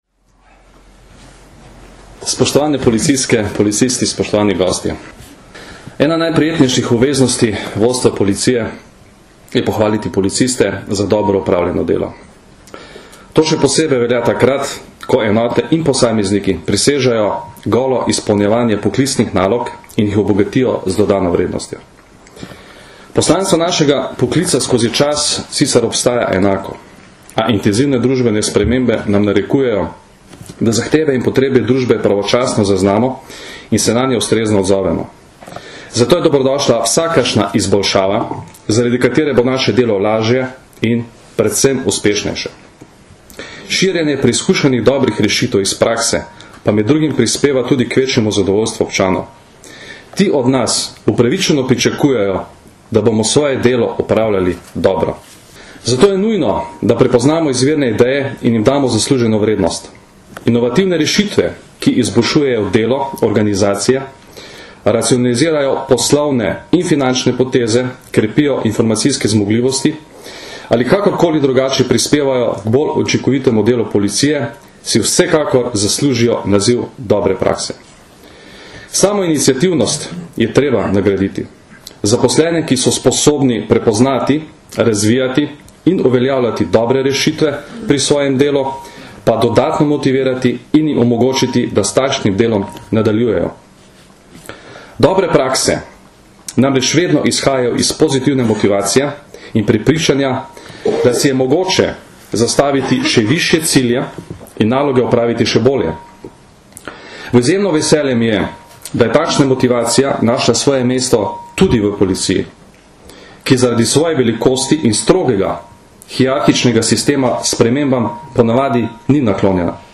V Policijski akademiji v Tacnu smo danes, 15. aprila 2010, organizirali konferenco o dobrih praksah v slovenski policiji in podelili priznanja avtorjem najboljših dobrih praks za leto 2009.
Zvočni posnetek izjave Janka Gorška (mp3)